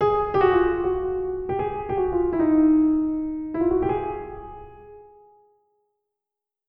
Piano-Inciso-Voce.wav